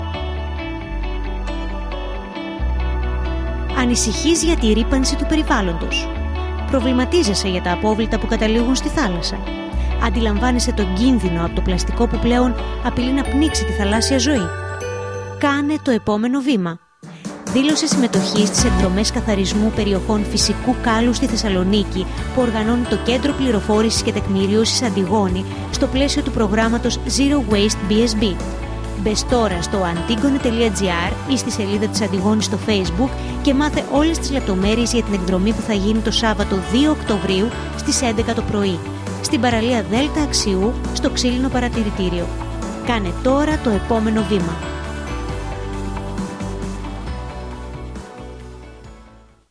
ραδιοφωνικό σποτ της ΑΝΤΙΓΟΝΗΣ καλεί τους πολίτες που ανησυχούν για τη ρύπανση του περιβάλλοντος να κάνουν το επόμενο βήμα.